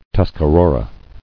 [Tus·ca·ro·ra]